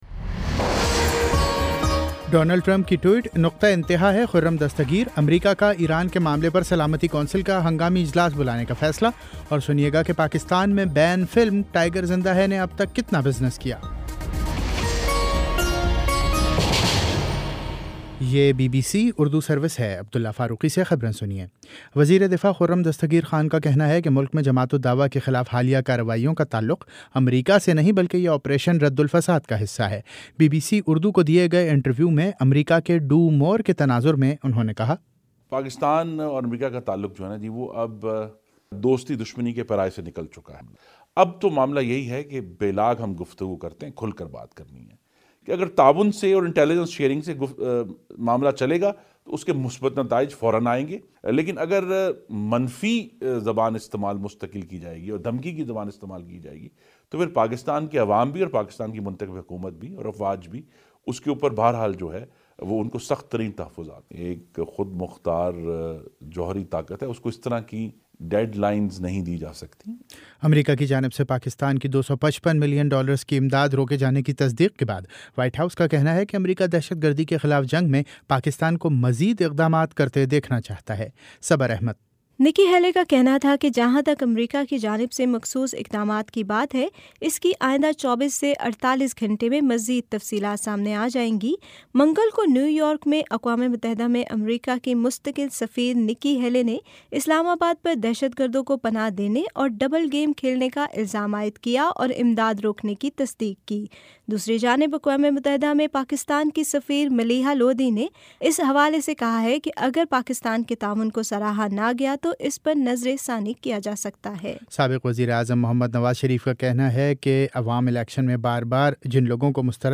جنوری 03 : شام سات بجے کا نیوز بُلیٹن
دس منٹ کا نیوز بُلیٹن روزانہ پاکستانی وقت کے مطابق شام 5 بجے، 6 بجے اور پھر 7 بجے۔